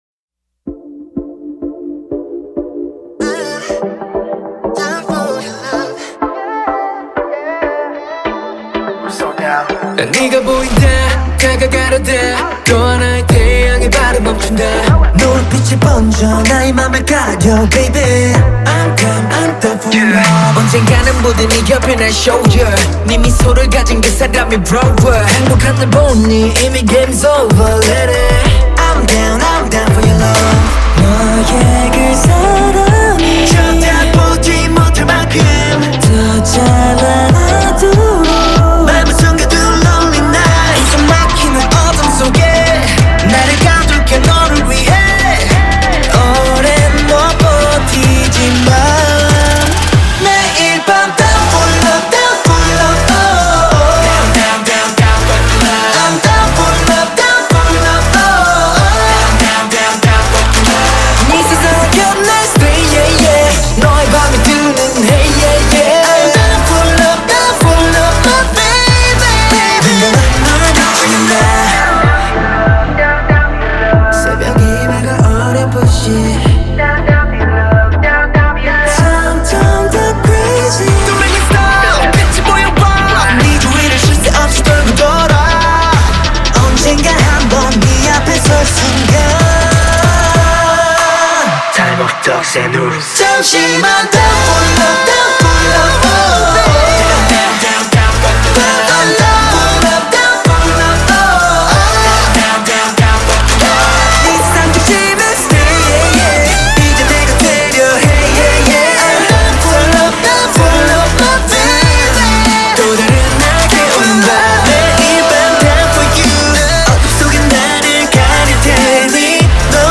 BPM95
MP3 QualityMusic Cut